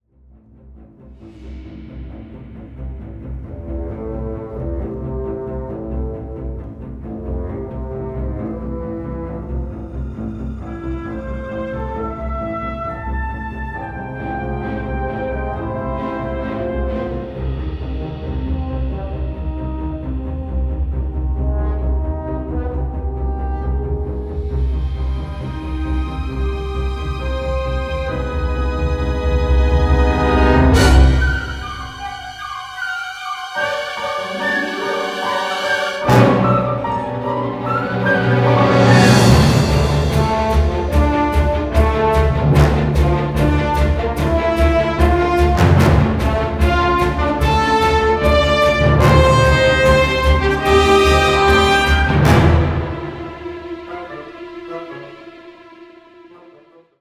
delivers a poignant and deeply moving symphonic score